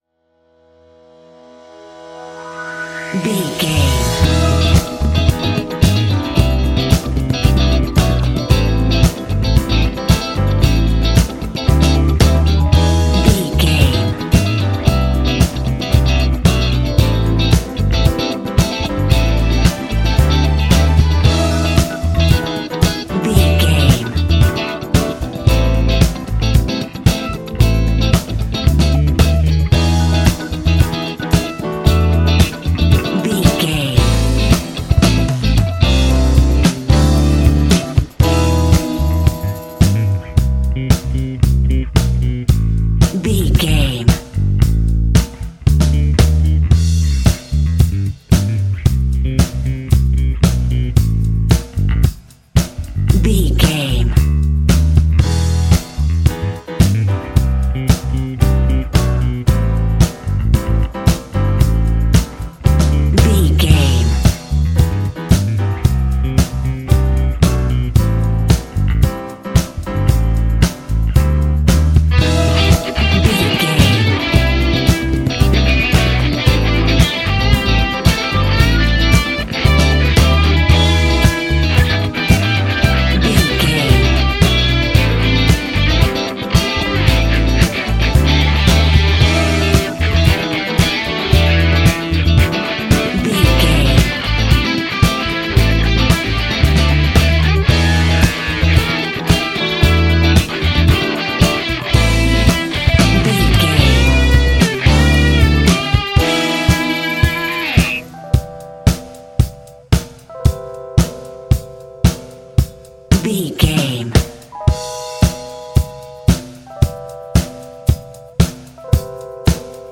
Ionian/Major
A♭
house
electro dance
Fast
synths
techno
trance
instrumentals